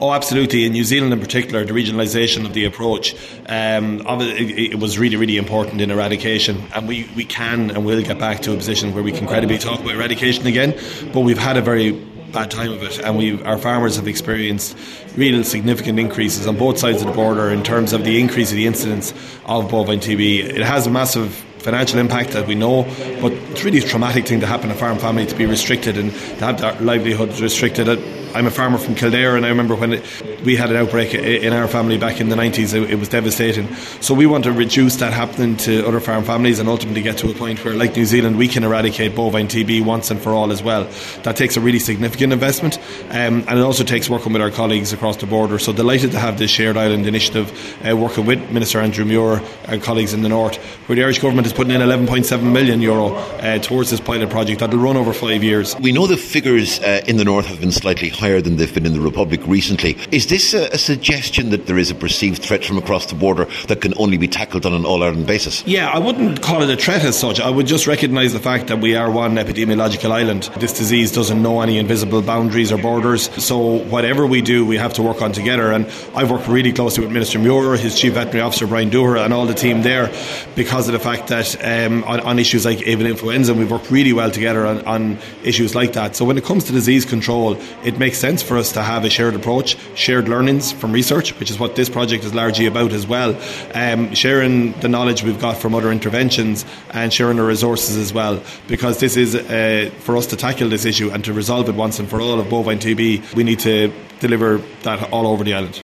A major new cross border pilot project to tackle the disease has been launched in Newtowncunningham today.